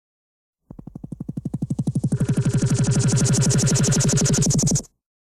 BSG FX - Launch tube lights illuminating
BSG_FX_-_Launch_Tube_Lights_illuminating.wav